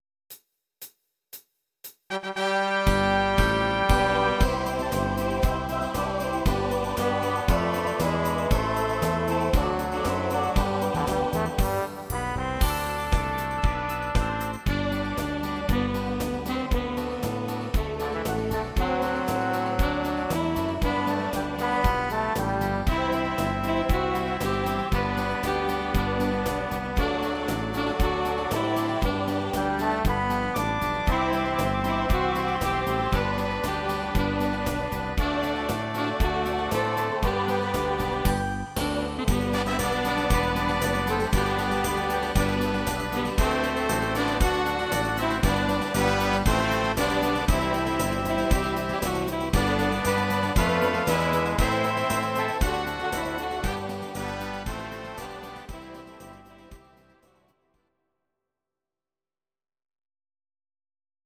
These are MP3 versions of our MIDI file catalogue.